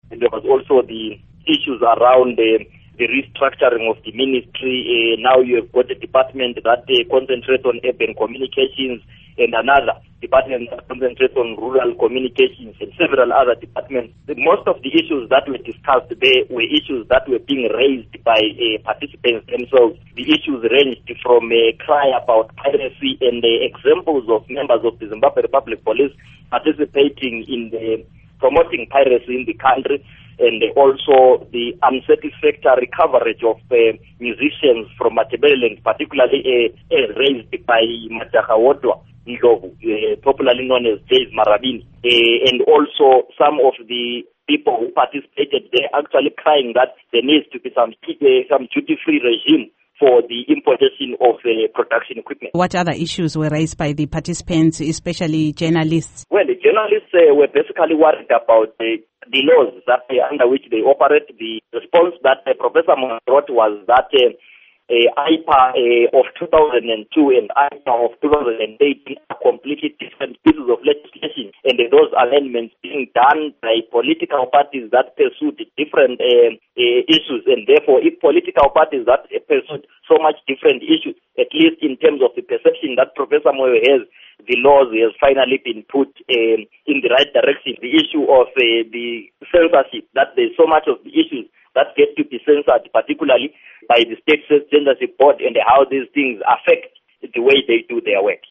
UMoyo ubekhuluma emhlanganweni lentathelizindaba, amabandla ezombusazwe, abalwela amalungelo oluntu, kanye labo somabhizimusi koBulawayo